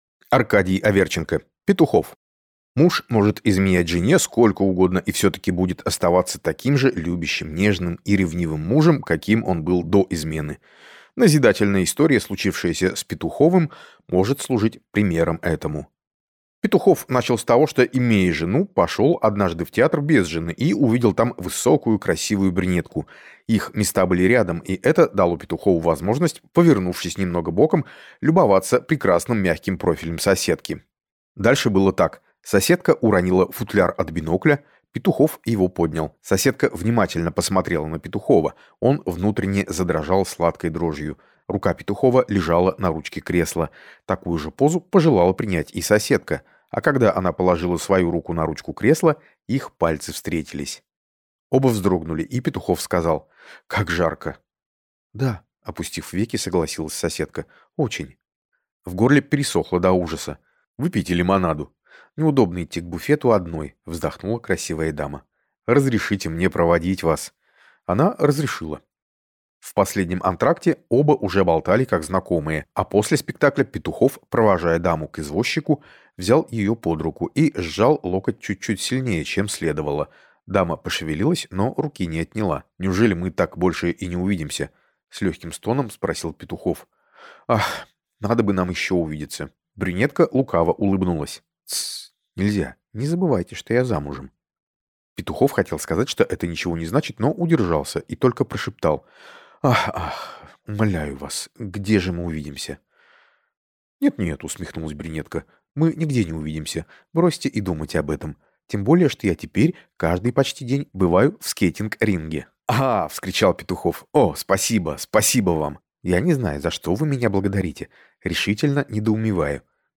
Аудиокнига Петухов | Библиотека аудиокниг